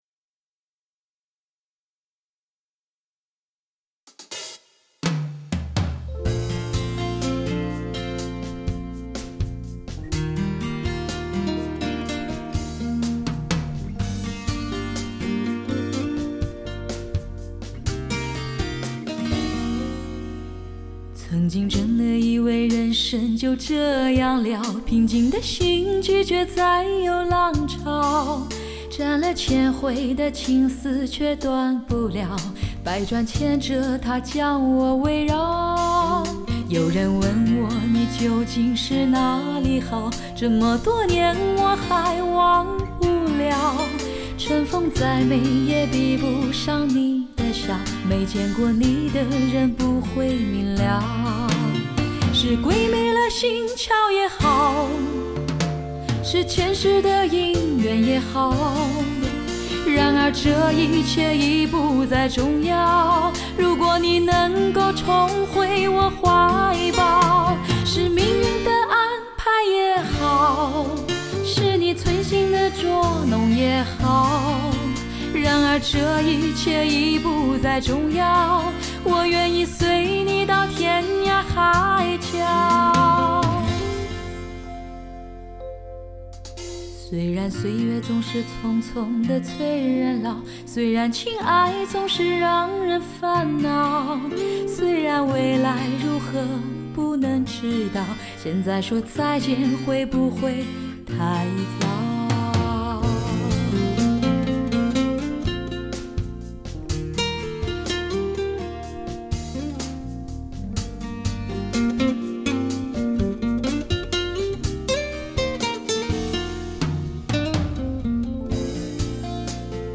神韵音色，再度重现，萦绕于耳畔的神话般美妙单色，令你惊叹不已。
具备高保真的声音品质和最自然传神的聆听效果。